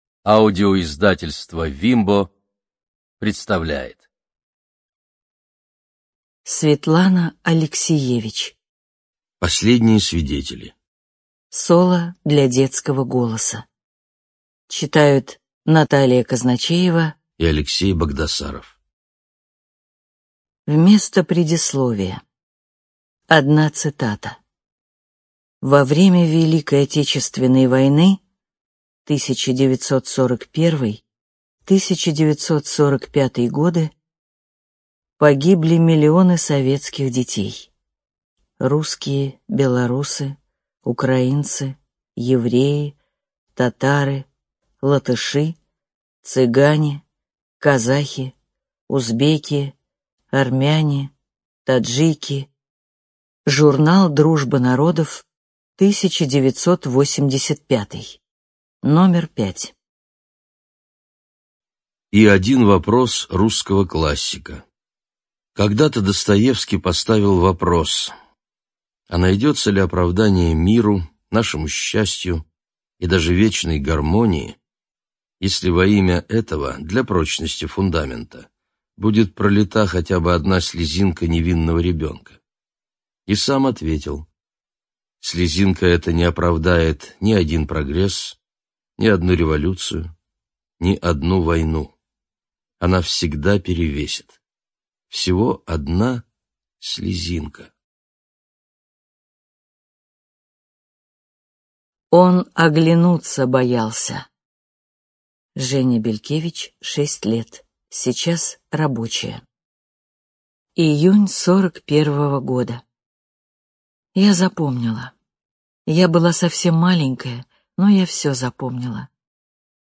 Студия звукозаписивимбо